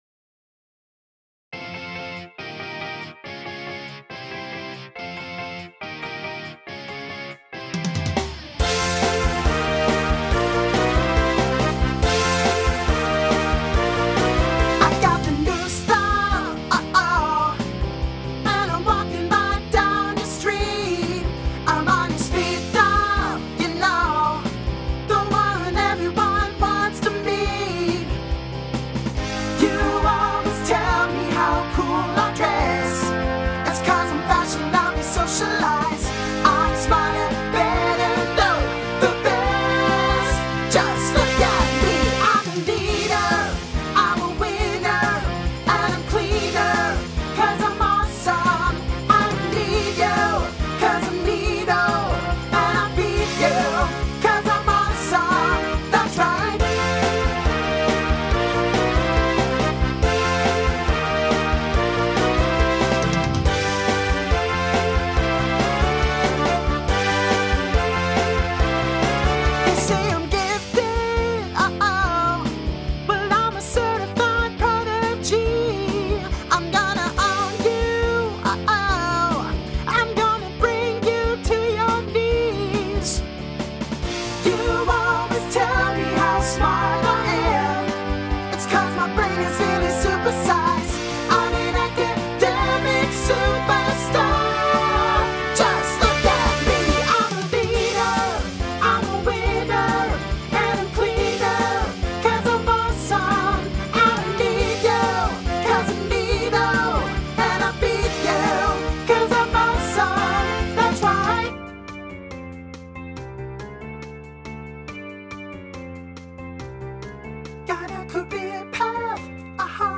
SSA Instrumental combo
Pop/Dance